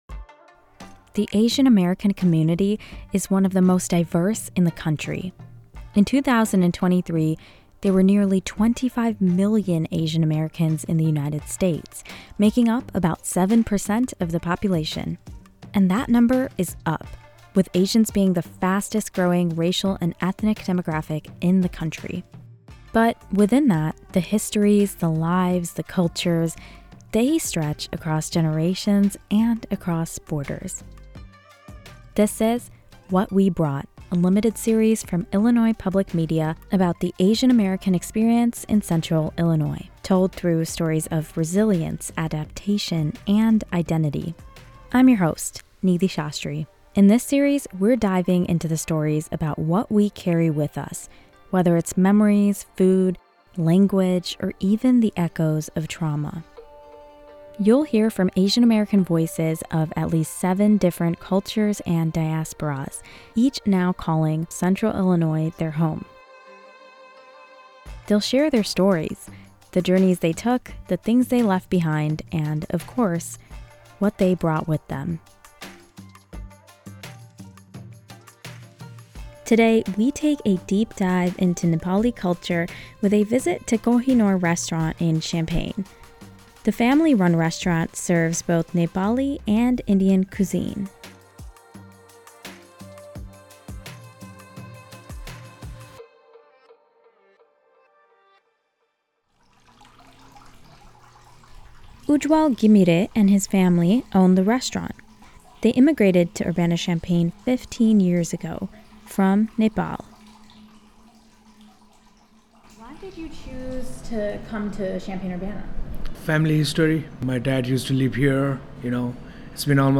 You’ll hear from Asian American voices of at least seven different cultures and diasporas, each now calling Central Illinois their home.